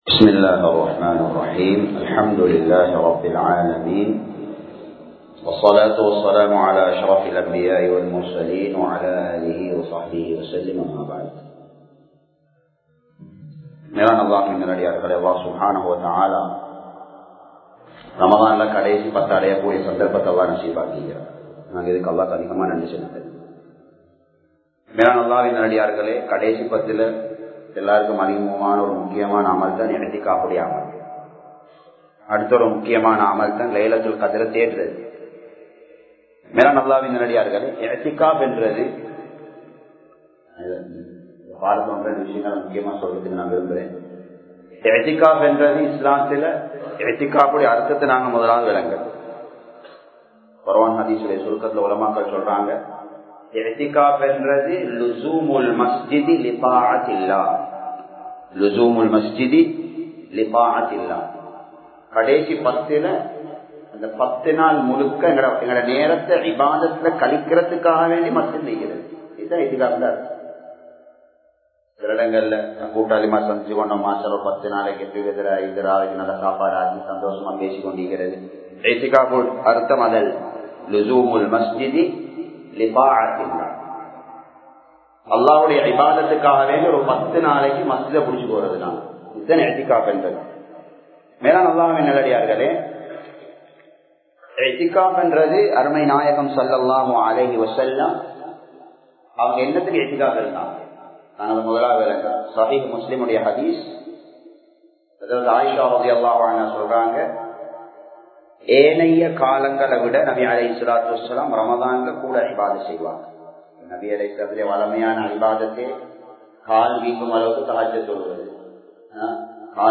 இஃதிகாபும் இறுதிப் பத்தும் | Audio Bayans | All Ceylon Muslim Youth Community | Addalaichenai
Muhiyadeen Jumua Masjith